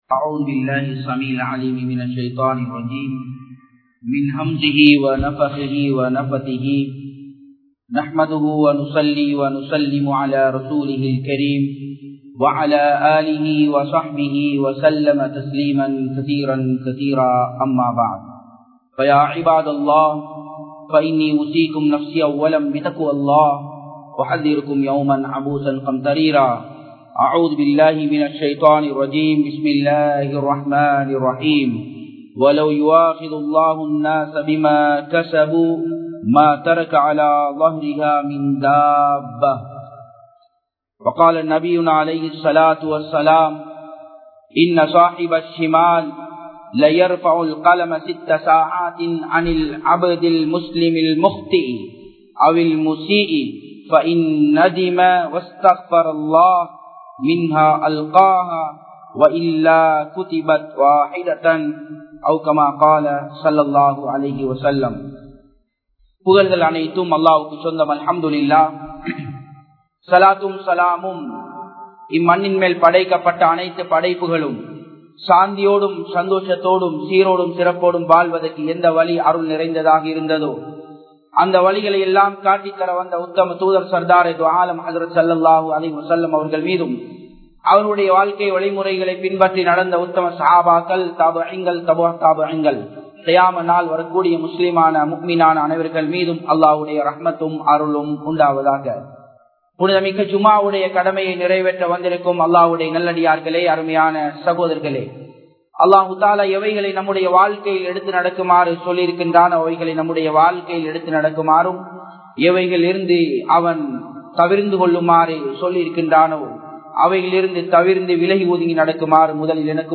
Maranaththitku Mun Thawba (மரணத்திற்கு முன் தௌபா) | Audio Bayans | All Ceylon Muslim Youth Community | Addalaichenai